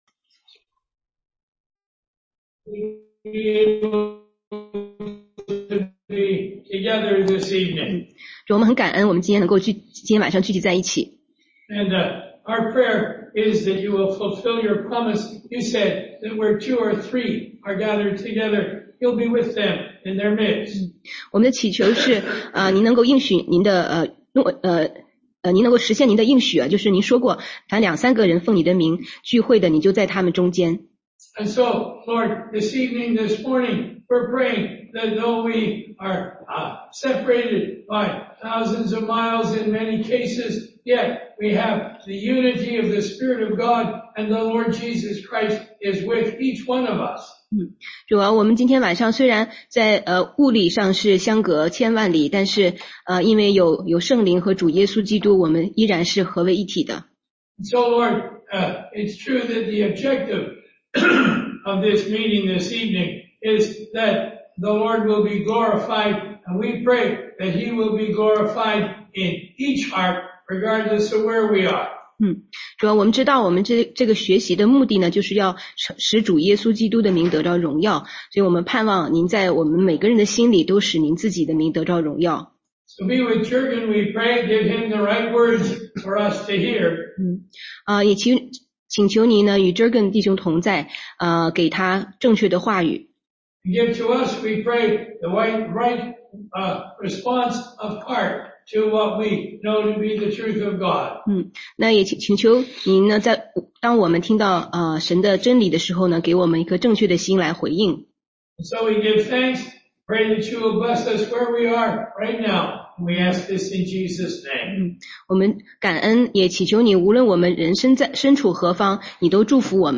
中英文查经